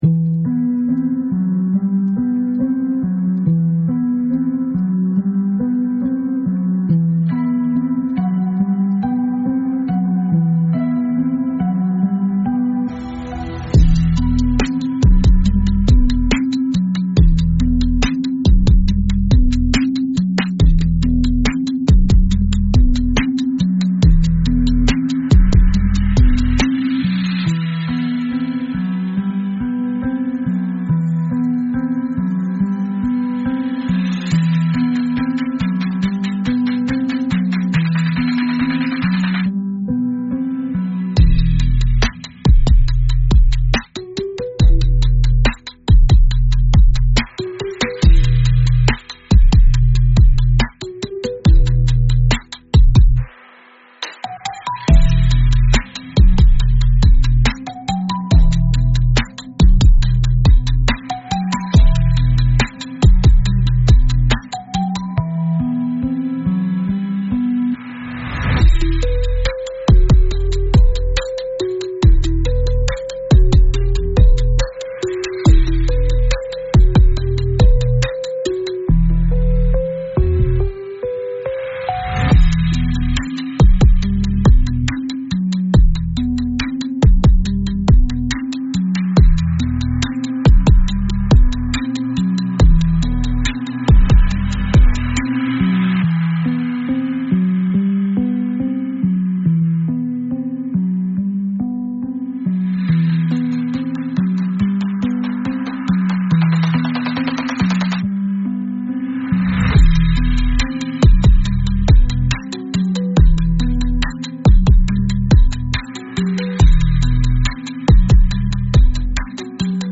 Instrumental - Real Liberty Media DOT xyz